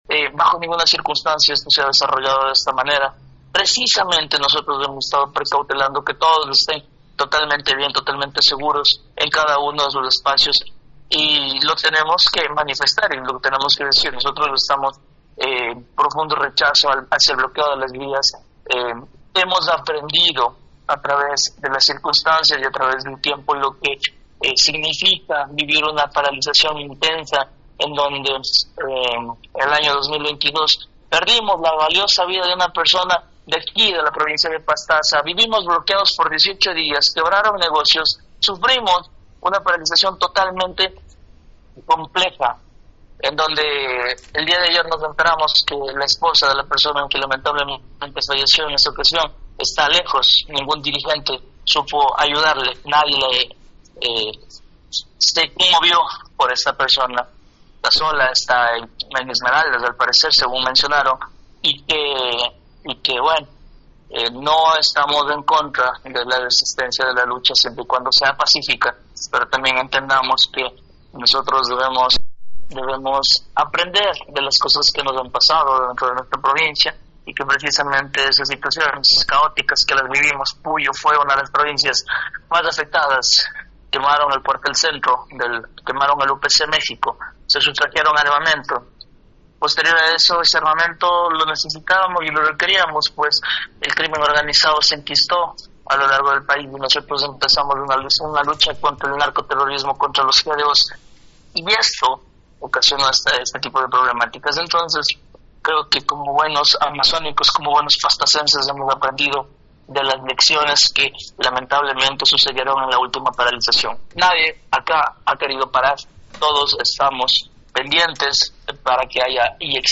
En una entrevista el día 29 de septiembre en Nina radio de Puyo, Rolando Ramos, gobernador de Pastaza, reafirmó que en esta provincia las actividades cotidianas se desarrollan con absoluta normalidad, el comercio, el transporte, las actividades escolares no han sido interrumpidas por la movilización.
Rolando Ramos, gobernador de Pastaza.